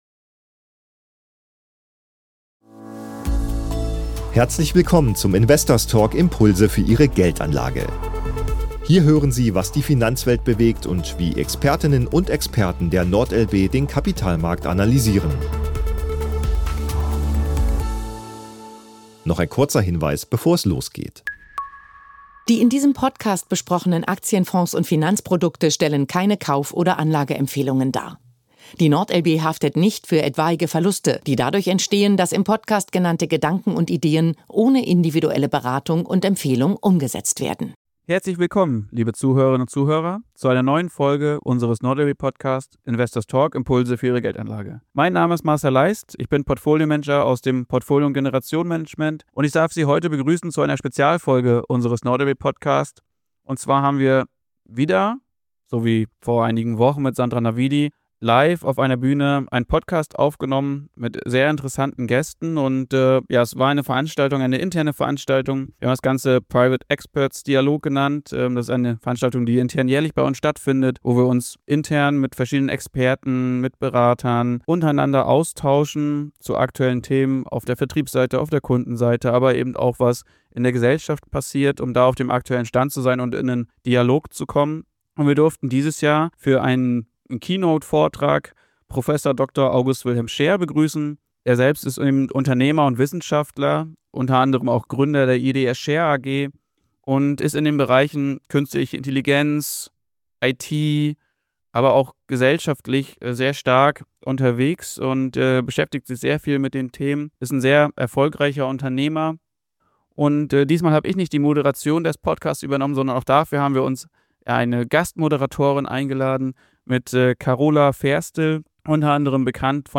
In dieser besonderen Ausgabe des Investors Talk – Impulse für Ihre Geldanlage präsentieren wir einen Live-Podcast, aufgezeichnet auf dem internen NORD/LB Event Private Experts Dialog.
Die Moderation übernimmt Carola Ferstl, bekannte Fernsehmoderatorin und erfahrene Wirtschaftsjournalistin. Ihr Gesprächspartner: Prof. Dr. August-Wilhelm Scheer, Unternehmer, Wissenschaftler und Digitalpionier. Carola Ferstl spricht mit Prof. Dr. August-Wilhelm Scheer über die Entwicklung der Künstlichen Intelligenz, den Vergleich zwischen dem heutigen KI-Hype und der Blase des Neuen Marktes sowie die Frage, ob sich erneut eine spekulative Übertreibung abzeichnet.